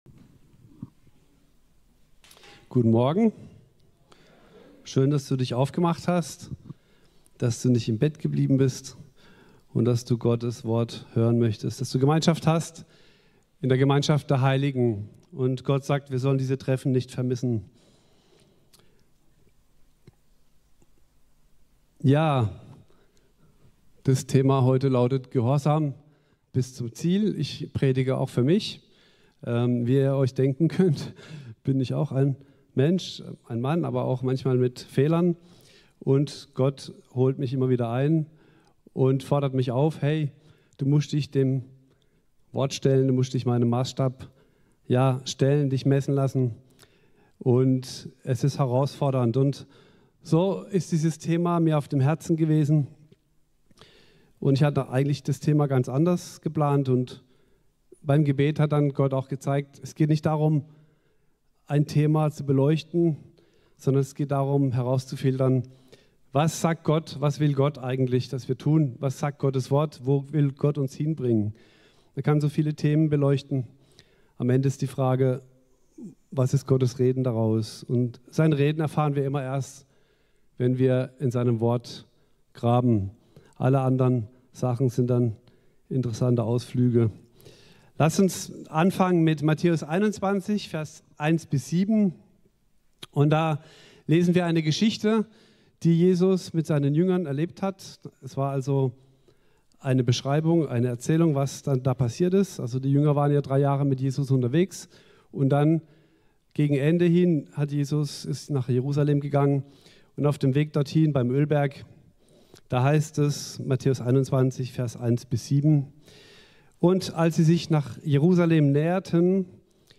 18.08.2024 Ort: Gospelhouse Kehl